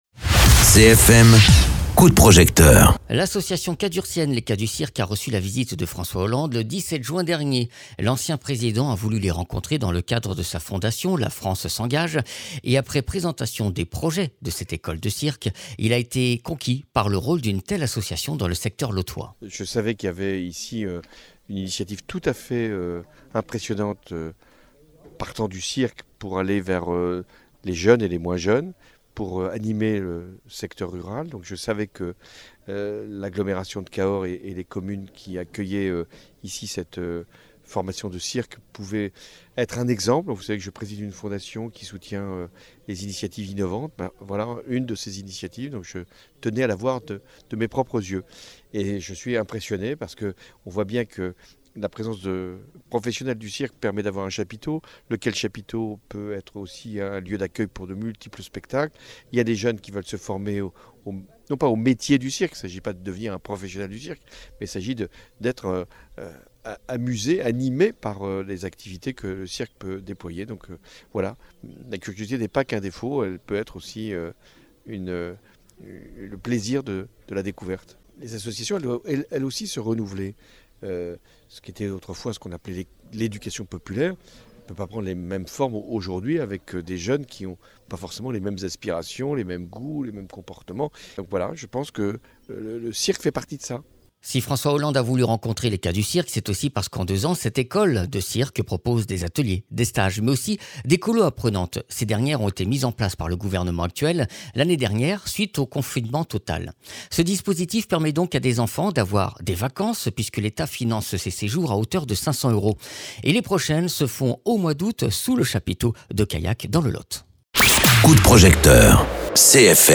Interviews
Invité(s) : François Hollande, ancien Président de la république